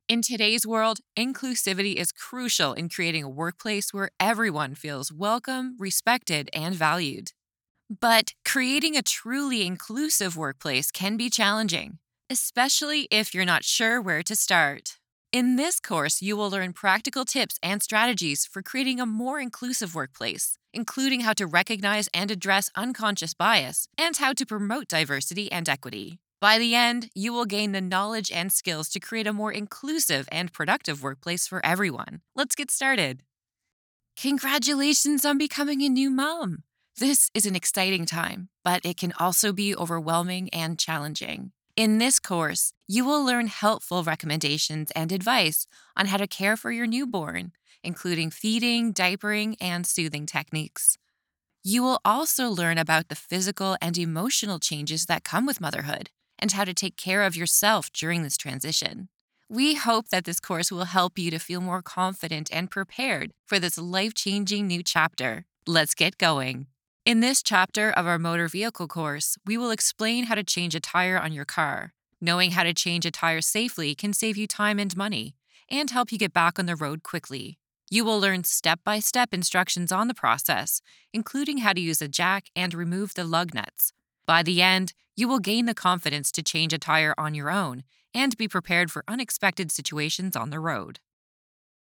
Elearning 2023
Canadian
Young Adult